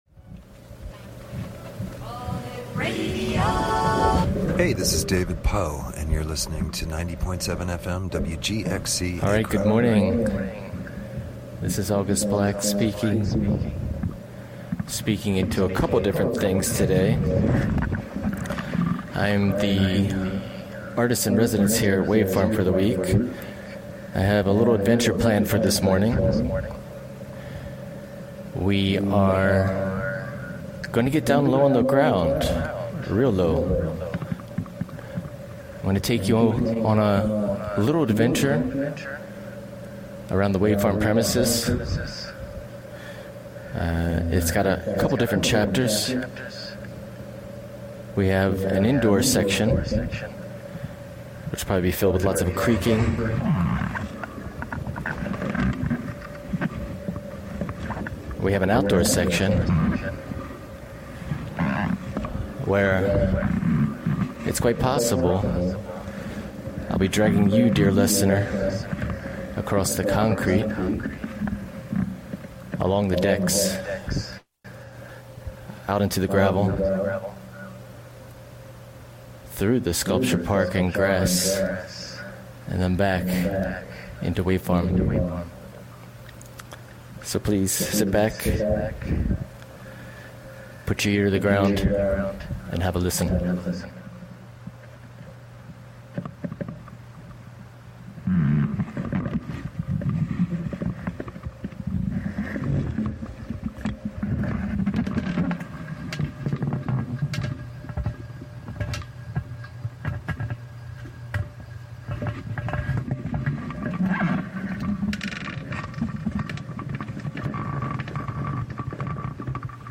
Custom cell phone footwear gives a parabolic shape to near-ground recordings